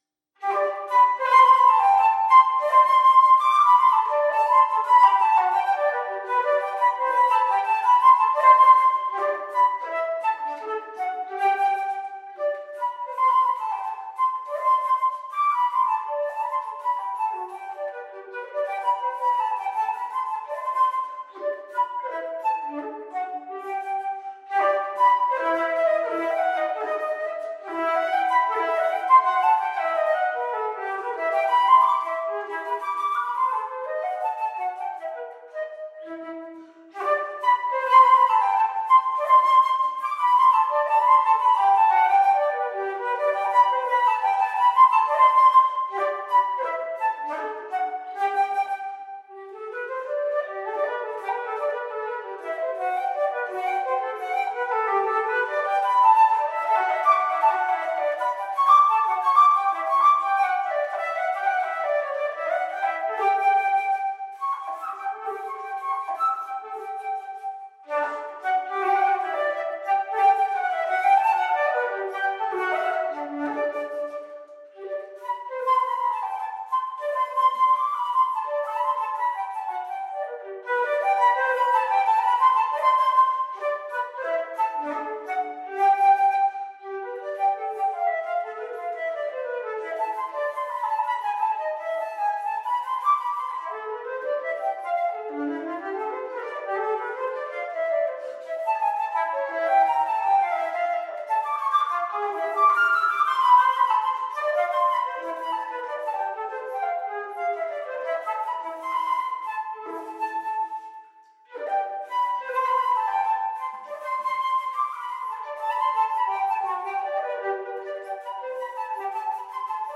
unaccompanied flute
dazzling and virtuosic transcriptions for solo flute